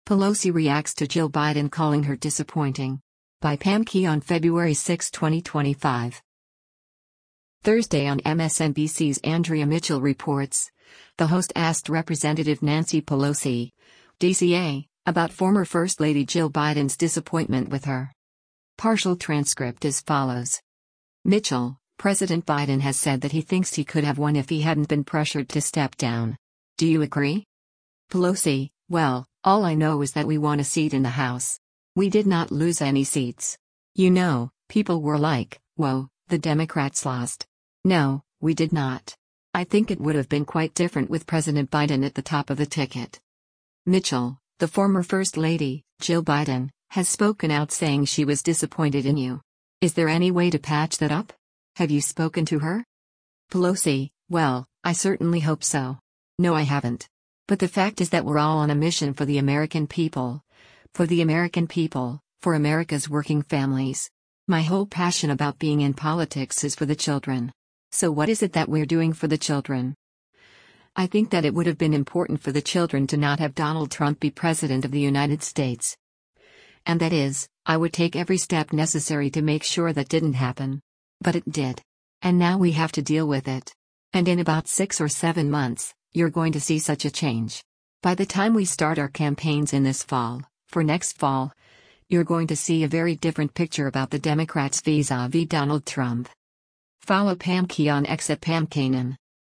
Thursday on MSNBC’s “Andrea Mitchell Reports,” the host asked Rep. Nancy Pelosi (D-CA) about former first lady Jill Biden’s disappointment with her.